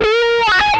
MANIC WAH 11.wav